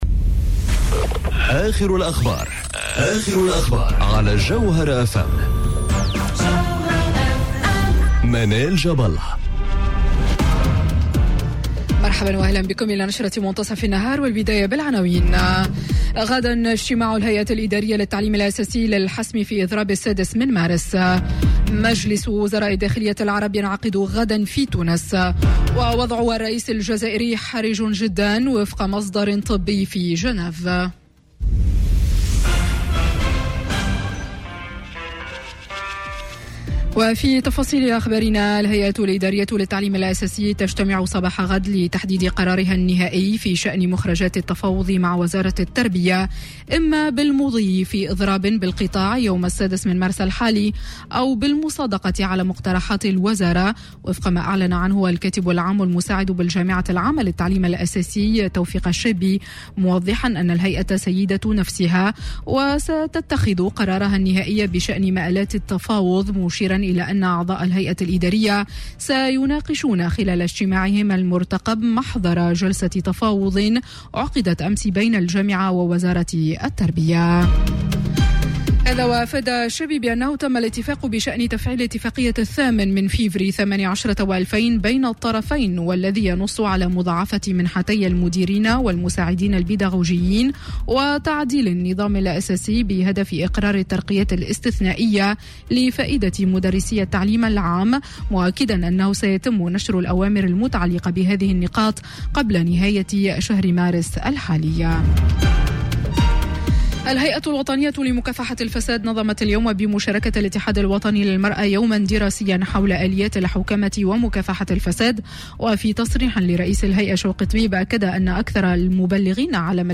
نشرة أخبار منتصف النهار ليوم السبت 02 مارس 2019